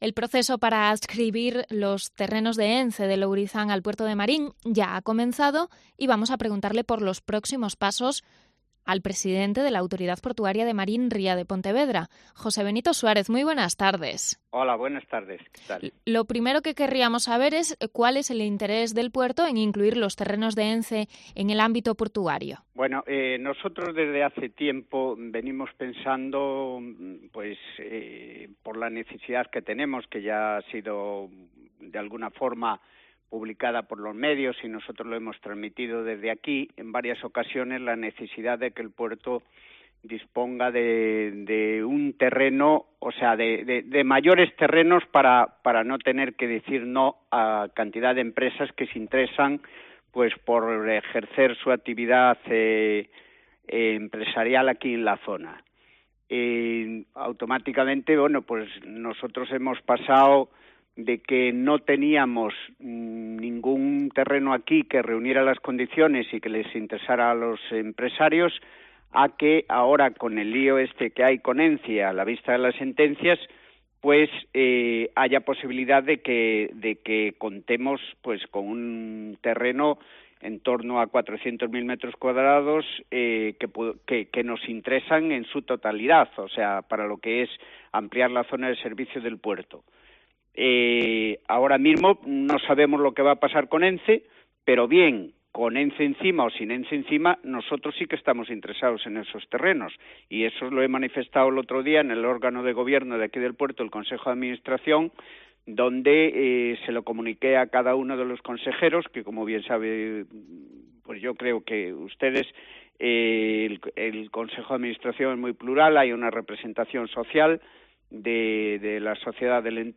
Entrevista al presidente del Puerto de Marín, José Benito Suárez